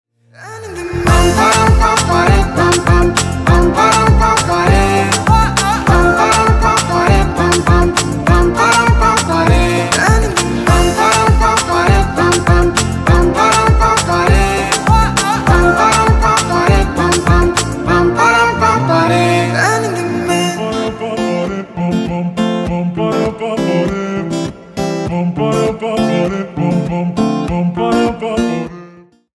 без слов
весёлые